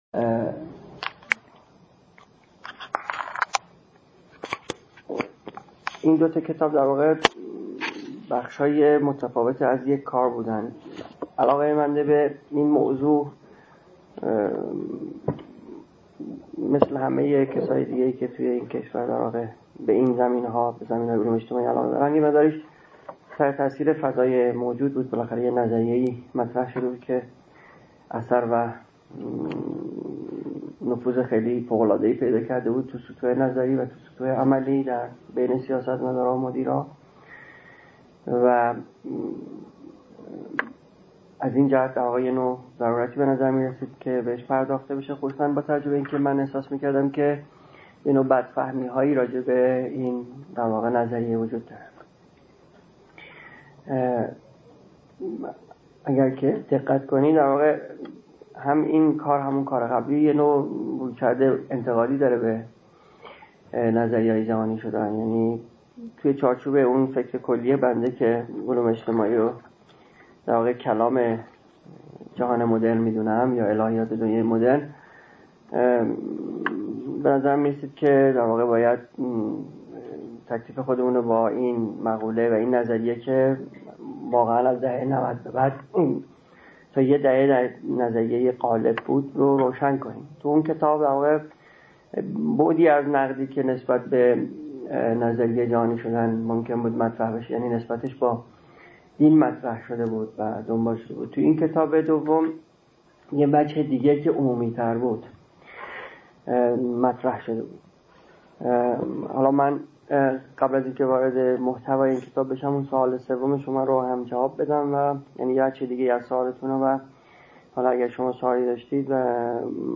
در دانشگاه علامه طباطبایی در تاریخ ۳۰/۰۹/۱۳۸۷ ایراد شده است.